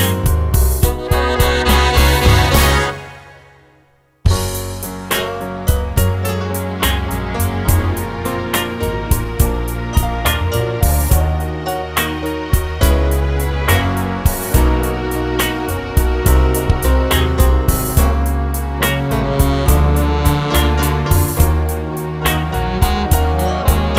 One Semitone Down Jazz / Swing 4:18 Buy £1.50